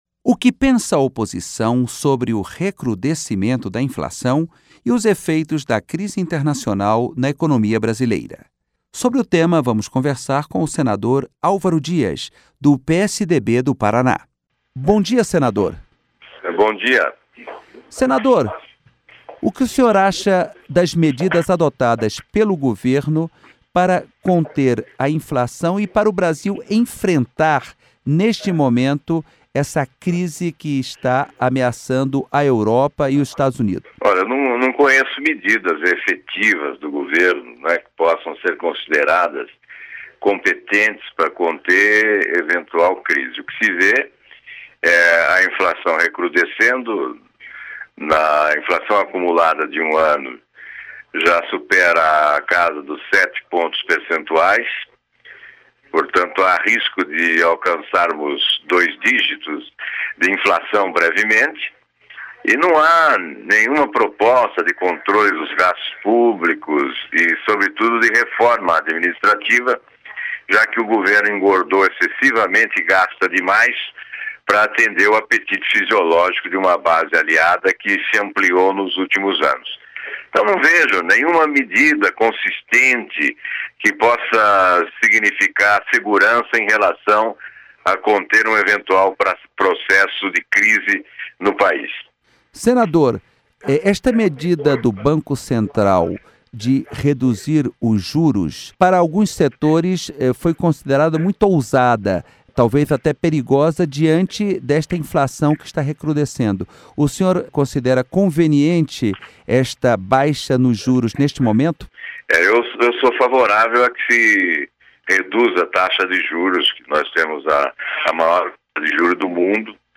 Senado Economia: Situação do Brasil diante da crise internacional Entrevista com o senador Alvaro Dias (PSDB-PR).